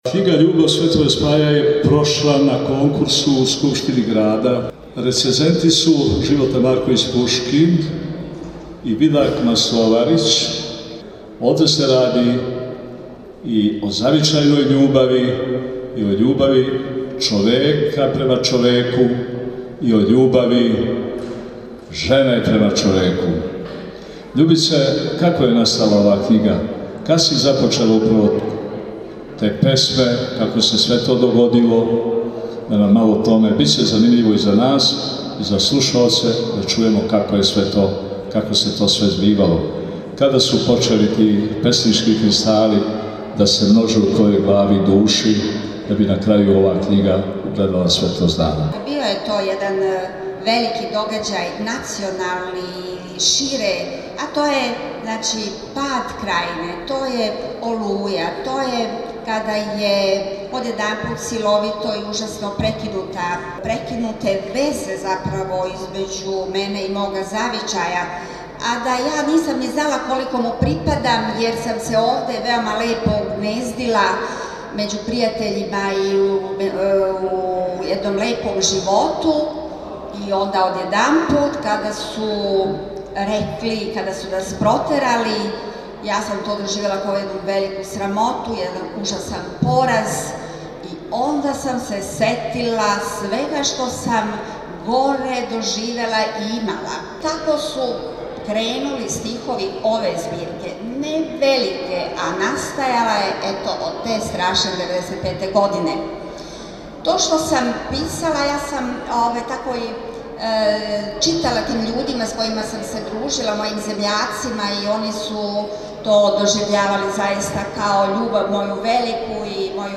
представљена је на Сајму књига и графике у Крагујевцу